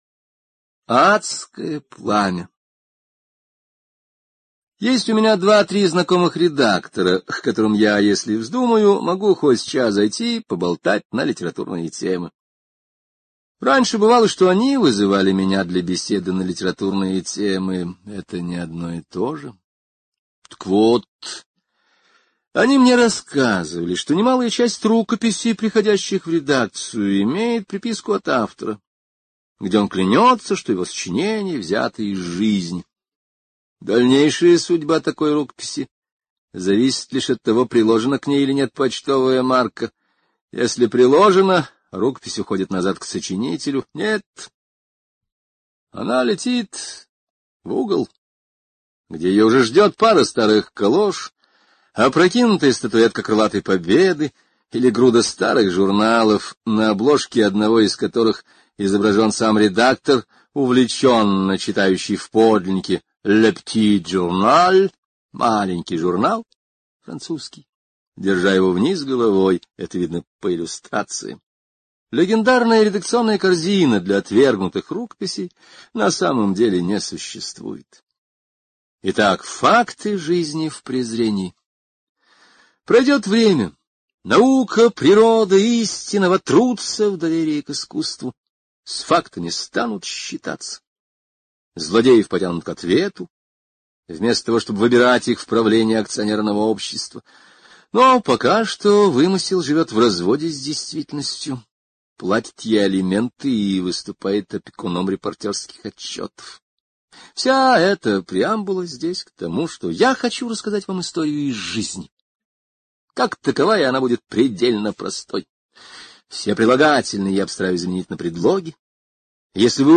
Адское пламя — слушать аудиосказку Генри О бесплатно онлайн